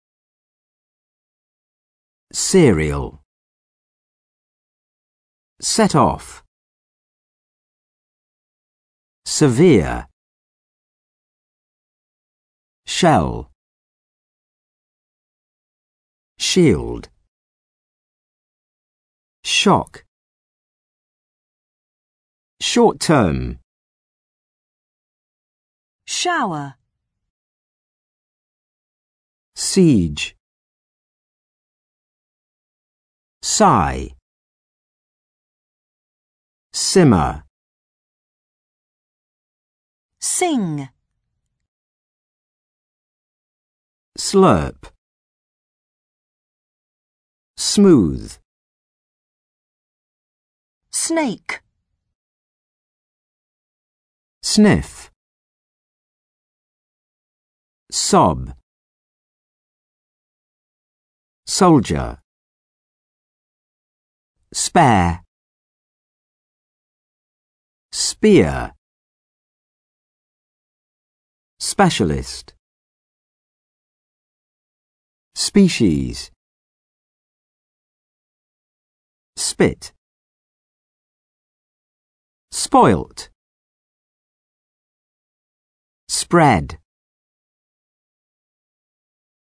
For your vocabulary and pronunciation practice, this glossary presents twenty-five words in alphabetical order with different phonetic sounds.